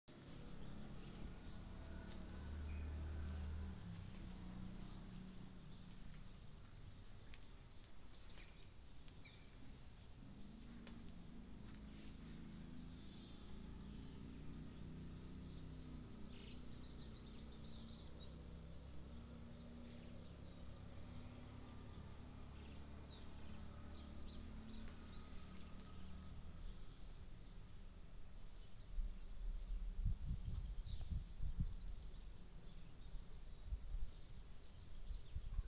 Tufted Tit-Spinetail (Leptasthenura platensis)
Country: Argentina
Province / Department: Catamarca
Condition: Wild
Certainty: Recorded vocal